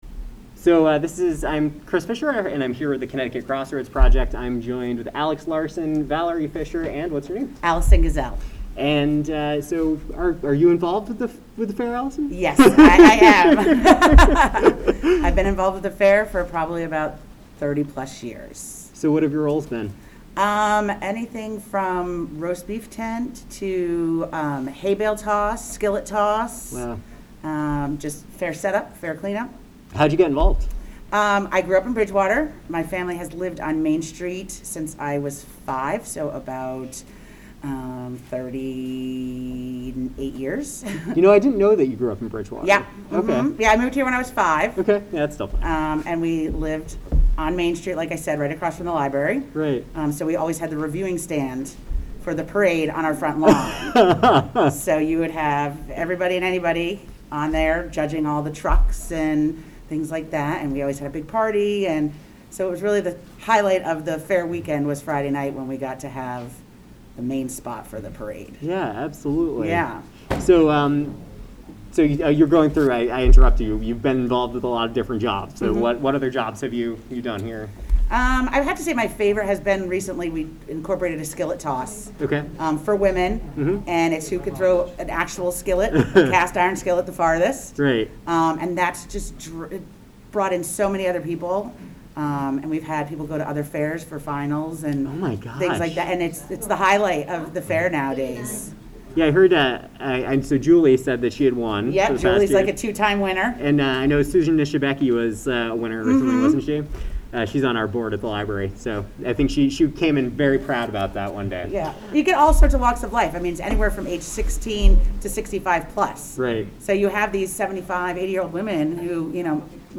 Oral History
Location Bridgewater Fire Department